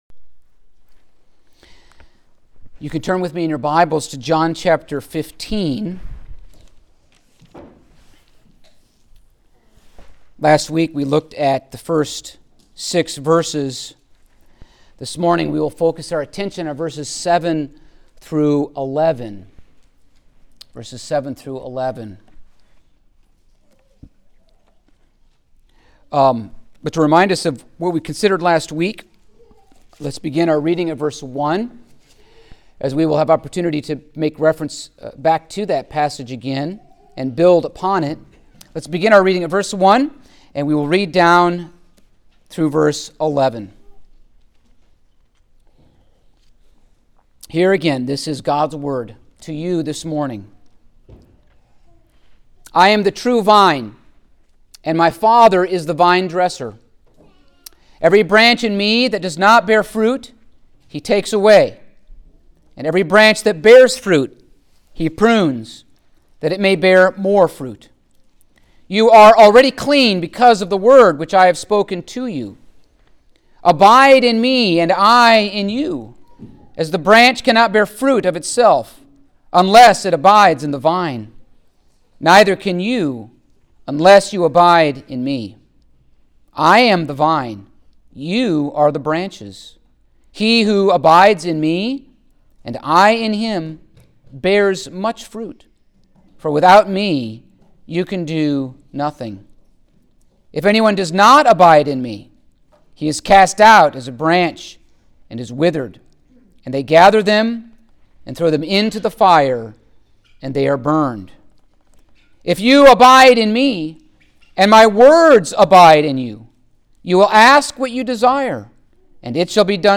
The Gospel of John Passage: John 15:7-11 Service Type: Sunday Morning Topics